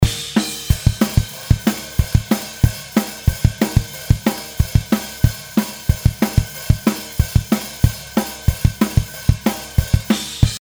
ということで、雑ですがひとまずPro-Gをデフォルトセッティングのまま挿してみました。
ミックスを進めていく段階でもっとセッティングを詰めていくと思うのですが、とりあえずはこのままで充分締まって聴こえてますね。